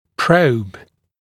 [prəub][проуб]зонд, зондирование, проба; зондировать, брать пробу, исследовать